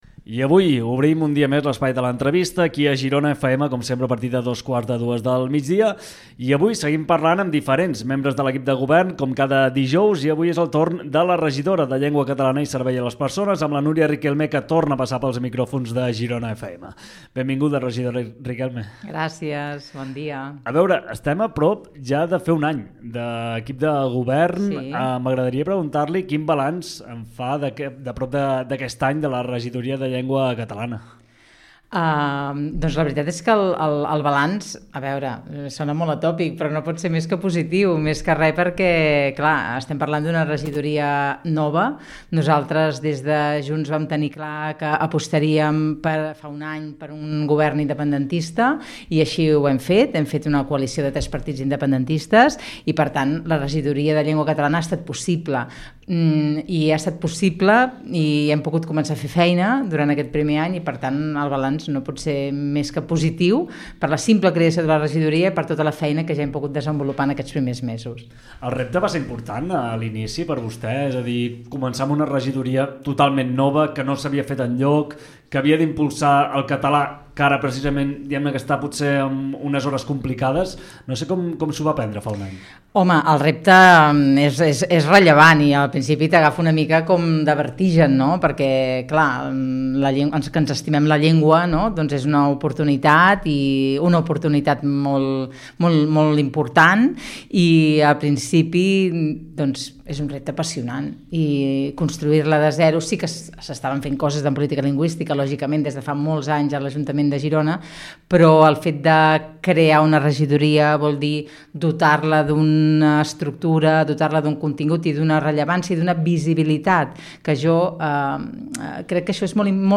Entrevista a Girona FM a Núria Riquelme, regidora de Llengua Catalana i Servei a les Persones - Junts per Girona